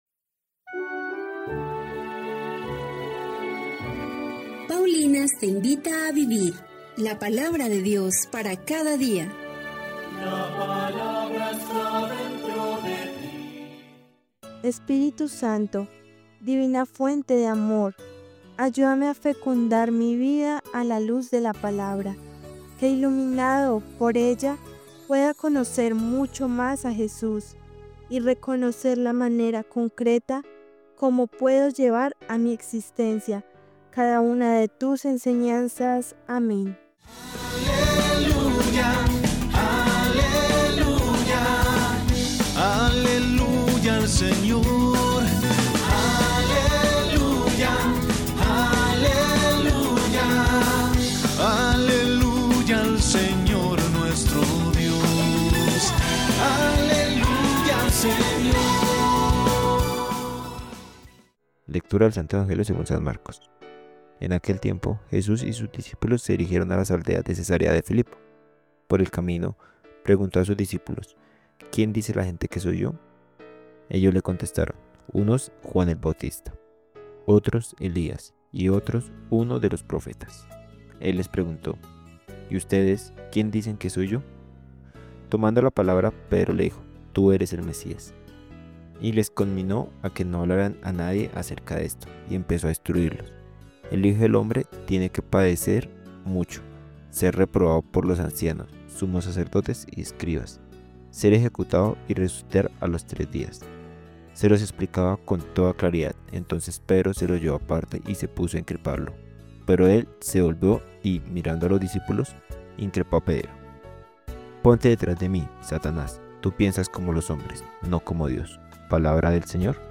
Lectura del libro de Isaías 55, 10-11